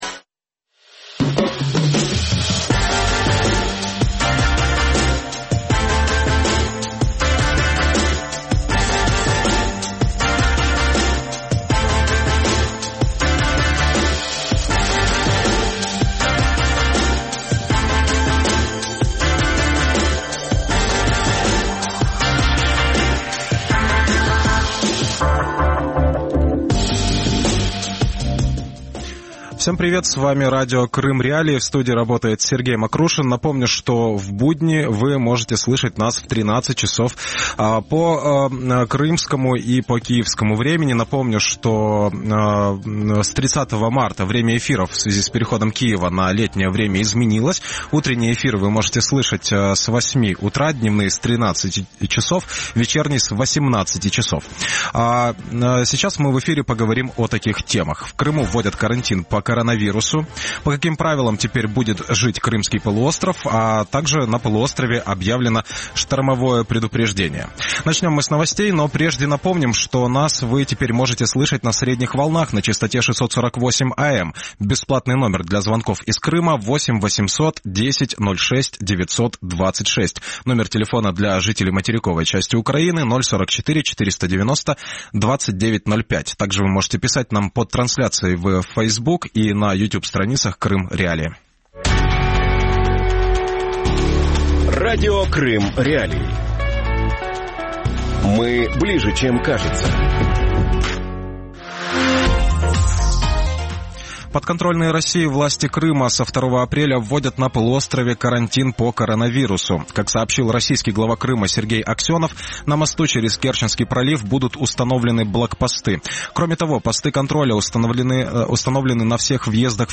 Крым уходит на карантин | Дневное ток-шоу